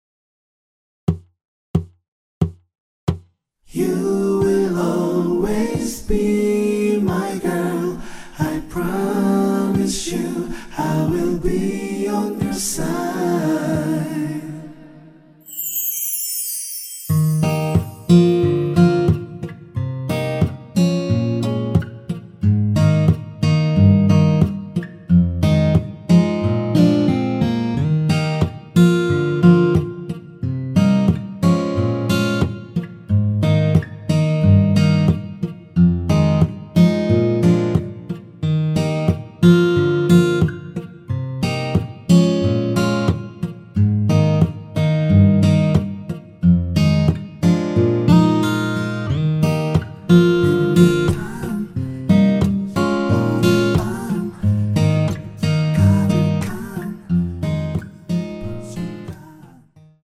전주 없이 무반주로 노래가 시작 하는 곡이라서
노래 들어가기 쉽게 전주 1마디 넣었습니다.(미리듣기 확인)
원키에서(-1)내린 코러스 포함된 MR입니다.
Eb
앞부분30초, 뒷부분30초씩 편집해서 올려 드리고 있습니다.
중간에 음이 끈어지고 다시 나오는 이유는